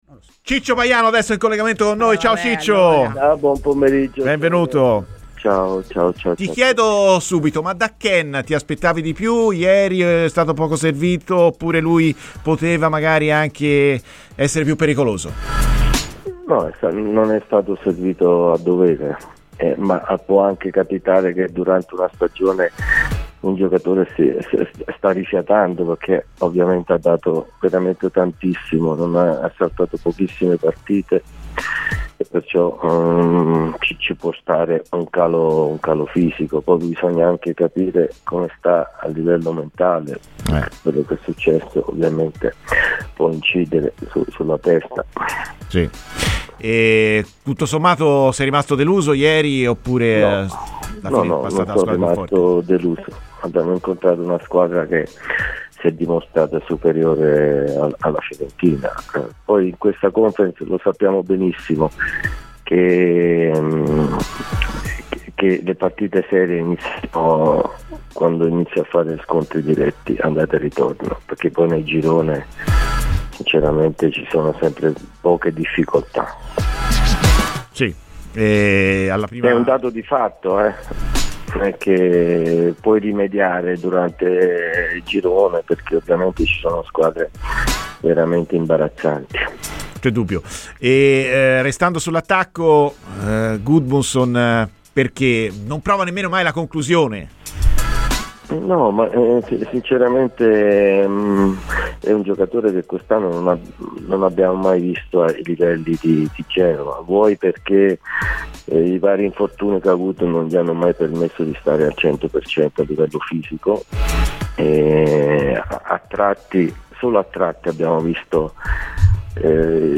Francesco "Ciccio" Baiano, ex attaccante viola, ha parlato ai microfoni di Radio FirenzeViola, durante "Viola Amore Mio", parlando così della prestazione di Moise Kean contro il Real Betis: "Non è stato servito a dovere. Può anche capitare che avesse bisogno di rifiatare, ci può stare un calo fisico. Bisogna anche capire come sta a livello mentale".